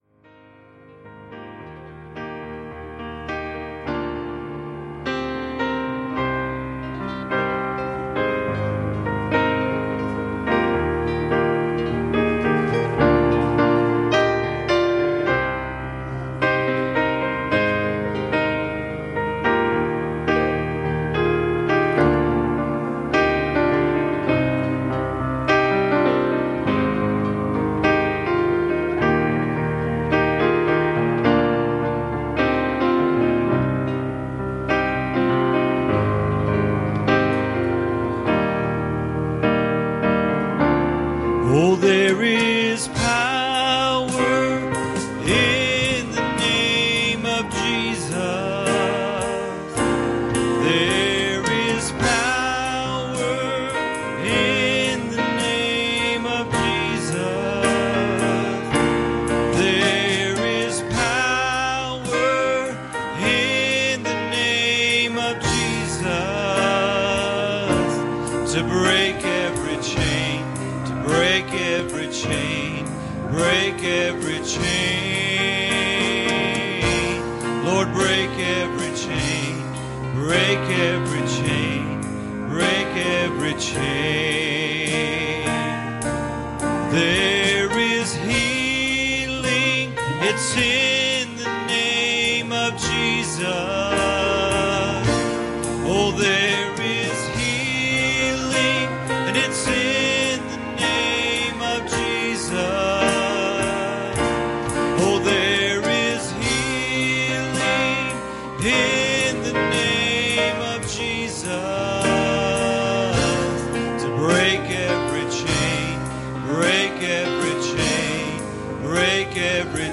Passage: John 13:3 Service Type: Wednesday Evening